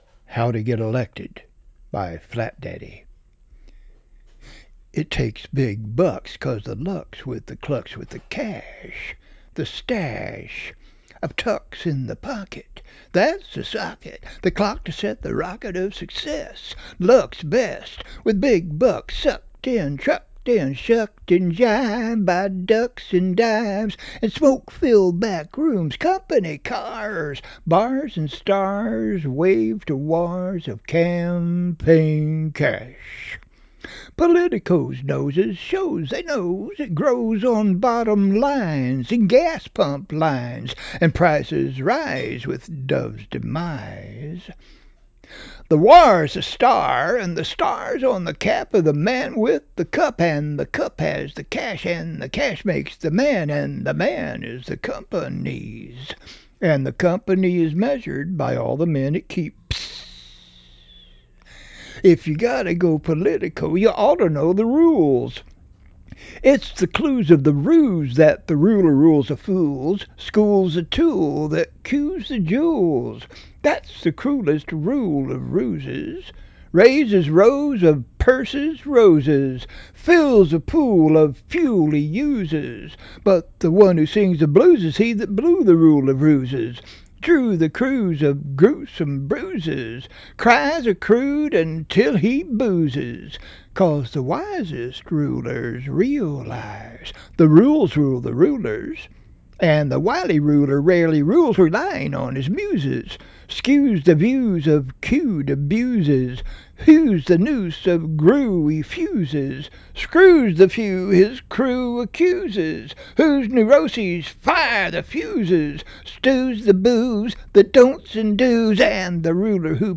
I performed this in various venues in Texas; it should be read/performed with a "beat," kinda jazzy.